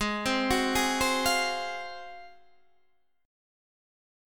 G#13 chord